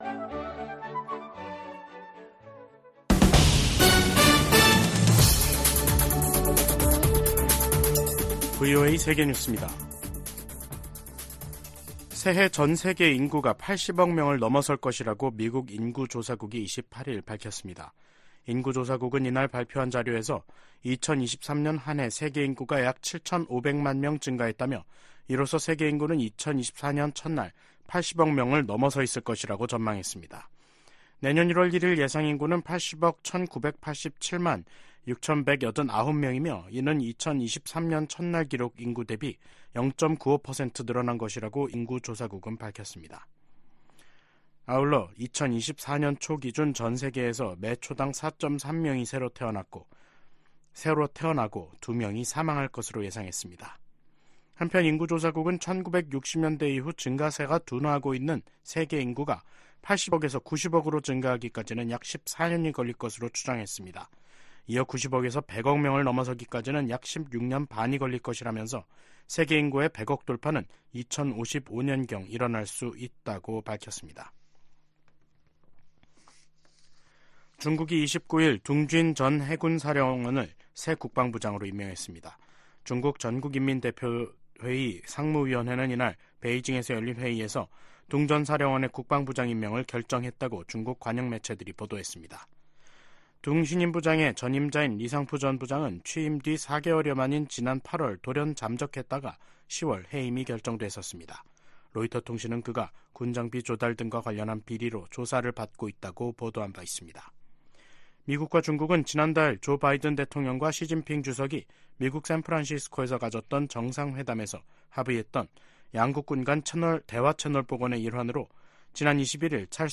VOA 한국어 간판 뉴스 프로그램 '뉴스 투데이', 2023년 12월 29일 3부 방송입니다. 하마스가 북한의 유탄발사기 부품을 이용해 살상력이 큰 신무기를 만든 것으로 확인됐습니다. 미국 전문가들은 김정은 북한 국무위원장의 '전쟁준비 완성' 지시에, 핵 도발은 김씨 정권의 종말을 뜻한다고 경고했습니다. 미국 내 일각에선 북한 비핵화 대신 핵무기 감축 협상이 현실적인 방안이라는 주장이 나오고 있습니다.